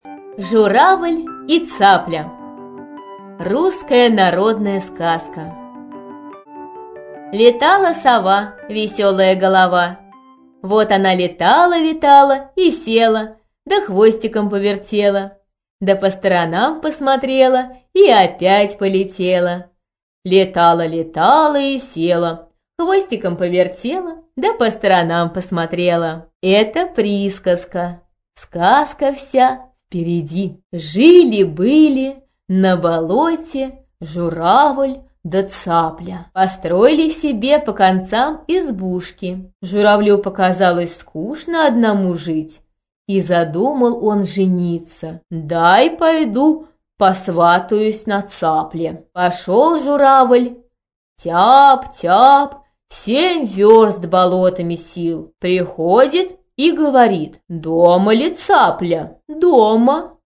Аудиокнига Журавль и Цапля | Библиотека аудиокниг